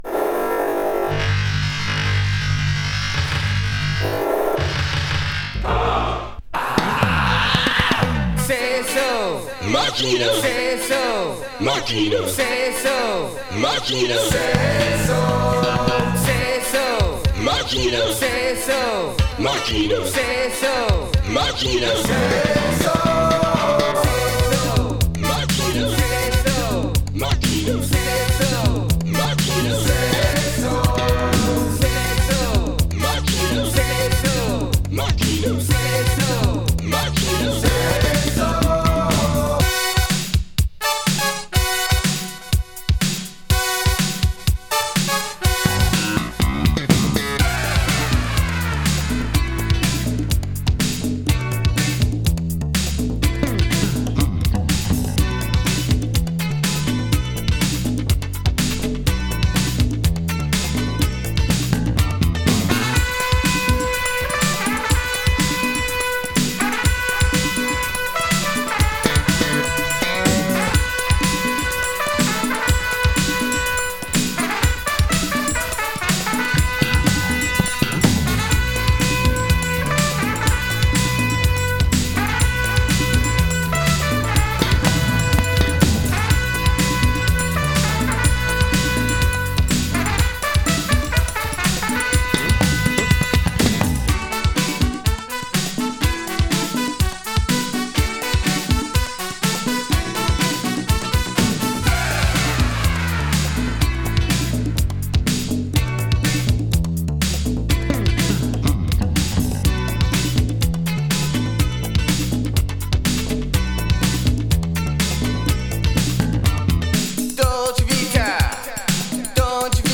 New Wave Funk！
【NEW WAVE】【DISCO】